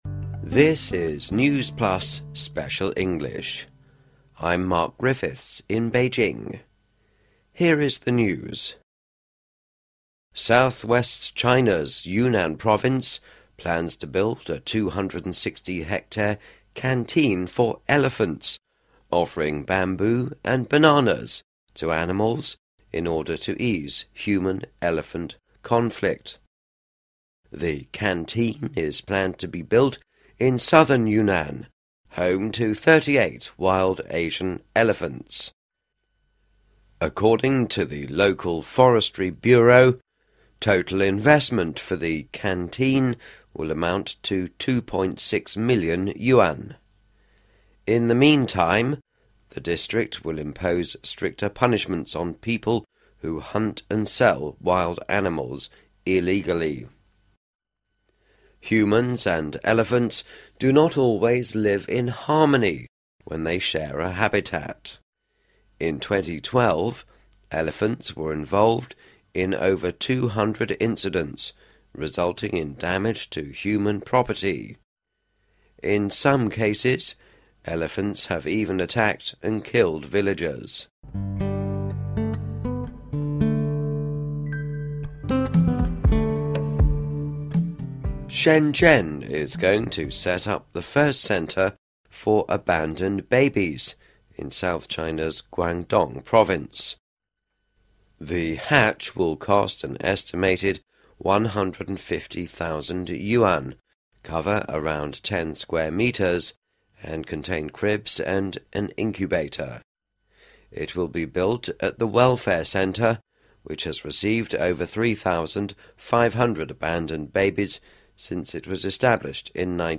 News Plus慢速英语:云南建大象食堂缓解人象矛盾 深圳将建广东首个弃婴岛